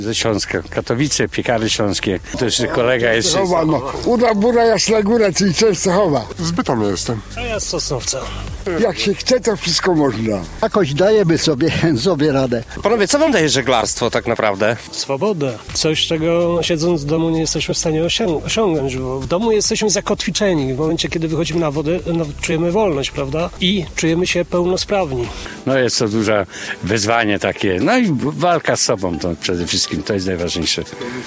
W porcie spotkaliśmy grupę niepełnosprawnych żeglarzy z południa Polski. Jak mówią, na wodzie czują się sprawni w pełni.